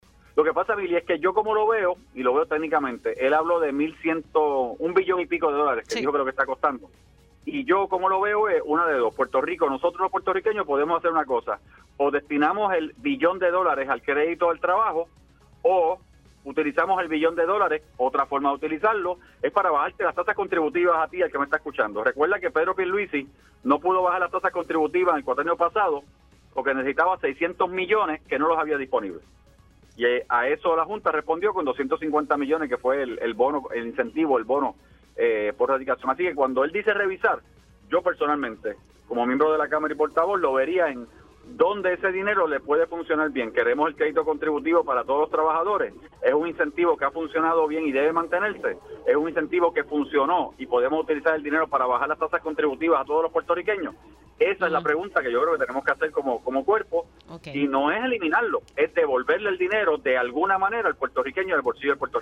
En el caso del legislador José ‘Pichy’ Torres Zamora, también rechazó eliminarlo y planteó que hay cuestionarse si dicho beneficio pudiera aplicarse a todos los trabajadores.